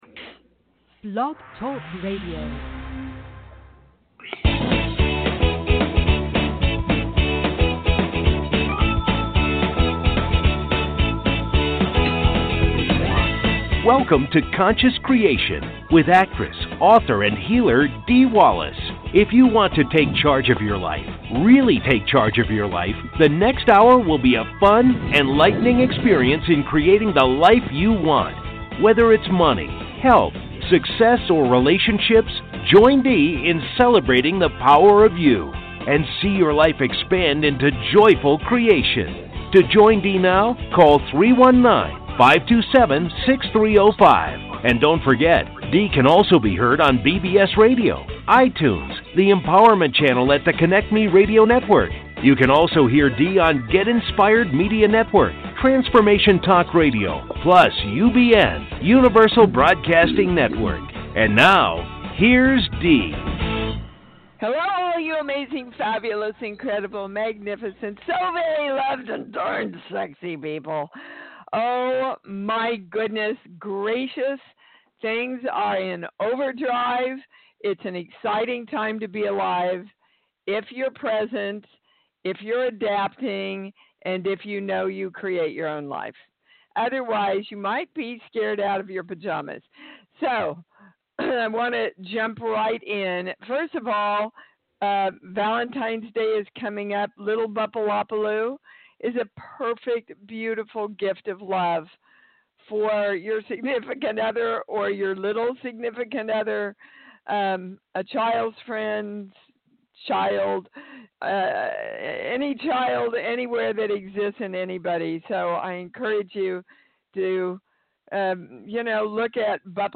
Talk Show Episode, Audio Podcast, Conscious Creation and with Dee Wallace on , show guests , about Spiritual Readings,Core Truths,Balanced Life,Energy Shifts,Spirituality,Spiritual Memoir,Healing Words,Consciousness,Self Healing,Teaching Seminars, categorized as Kids & Family,Paranormal,Philosophy,Spiritual,Access Consciousness,Medium & Channeling,Psychic & Intuitive